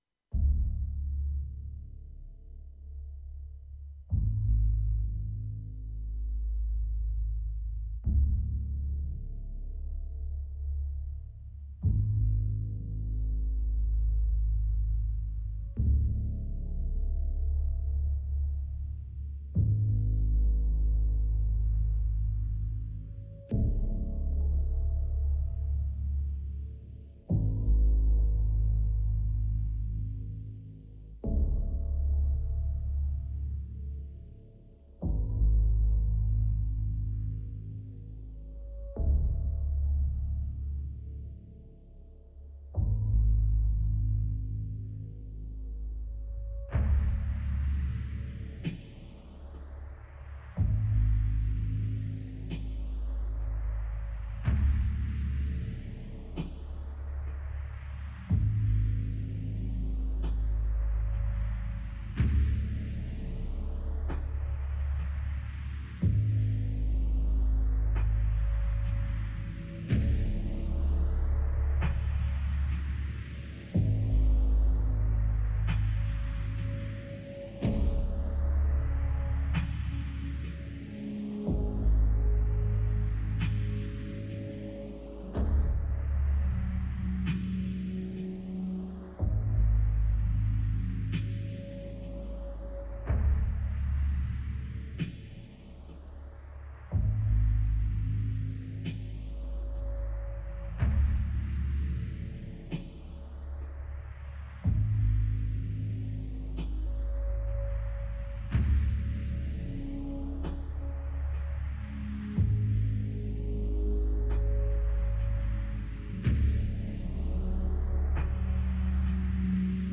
DARK AMBIENT / FREE JAZZ / DOWNTEMPO